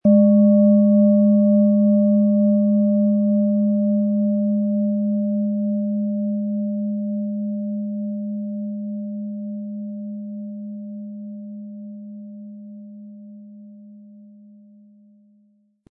Planetenschale® Visionen und übersinnliche Begabungen & Mystische Energie wahrnehmen mit Neptun, Ø 15,8 cm, 500-600 Gramm inkl. Klöppel
Planetenton 1
Von Hand getriebene tibetanische Planetenschale Neptun.